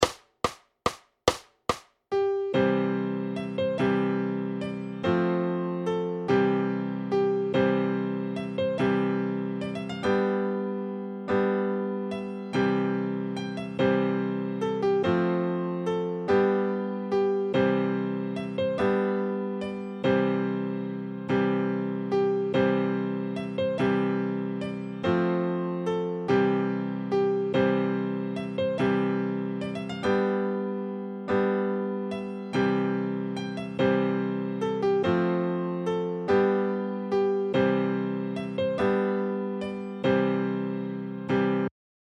Noty na snadný klavír.
Ke všem písním jsou připojeny zvukové demonstrační ukázky.
Hudební žánr Folk